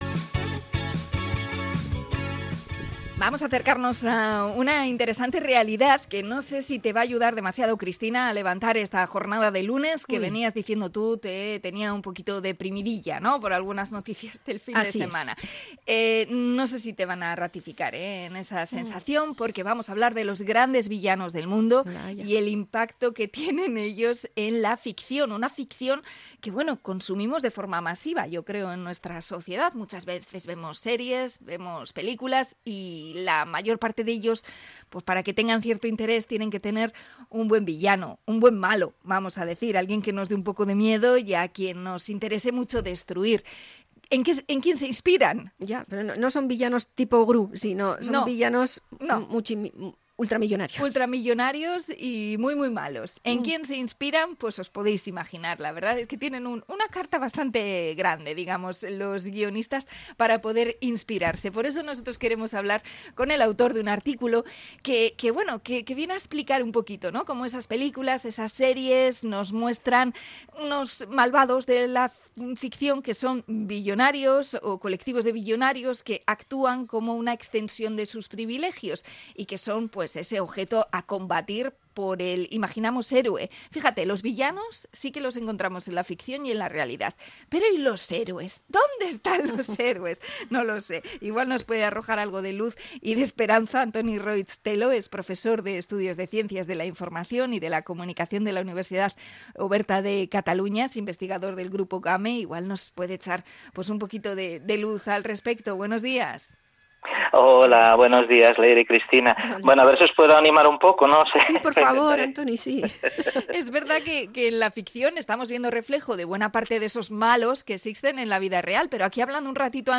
Entrevista a investigador sobre los nuevos villanos de películas y series